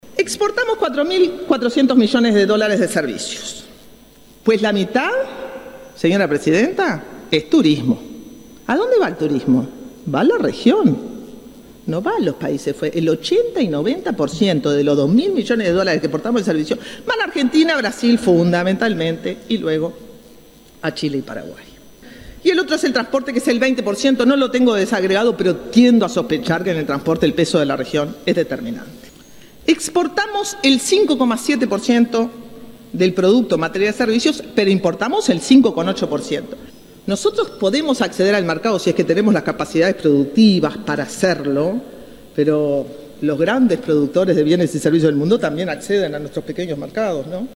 Interpelación en el Senado